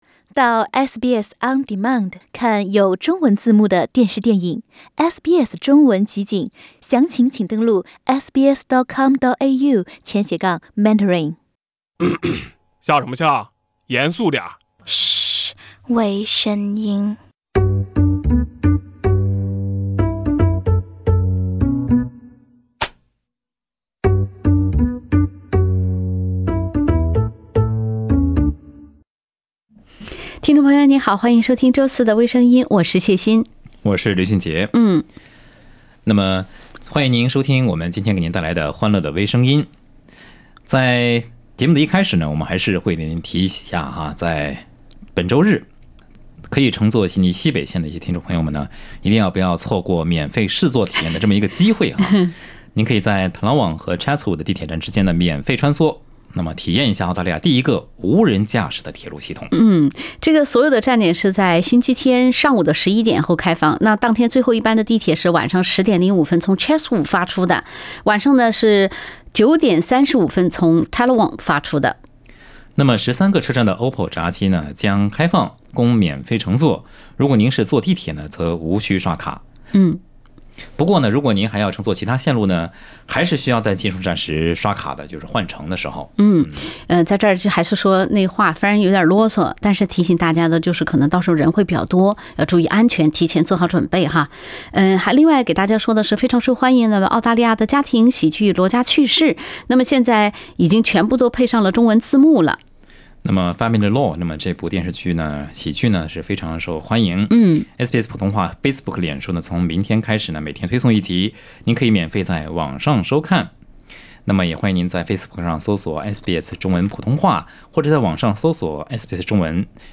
另类轻松的播报方式，深入浅出的辛辣点评，包罗万象的最新资讯，倾听全球微声音。